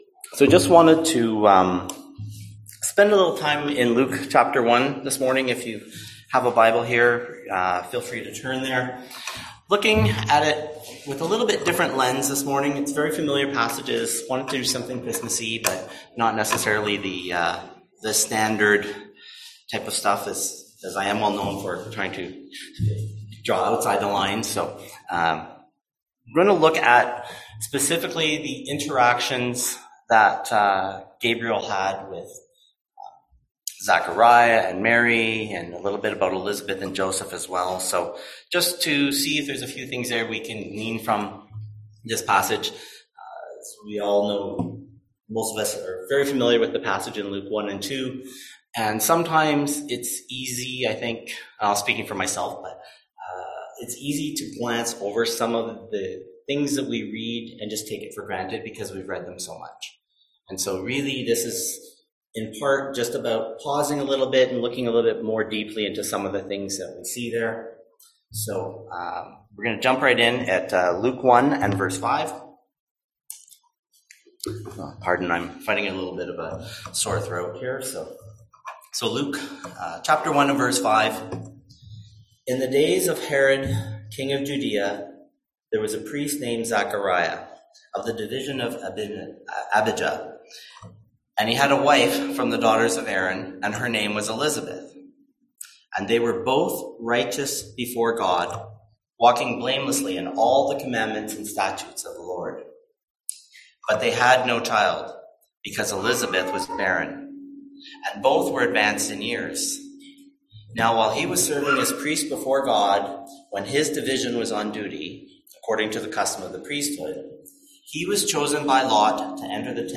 Passage: Matthew 1 Service Type: Sunday AM